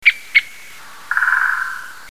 Pic épeiche
Espèce farouche et discrète, il est possible de l’entendre creuser sa cavité au printemps et de l’observer lorsqu’il nourrit ses poussins.
pic_epeiche_001.mp3